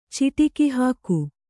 ♪ ciṭiki hāku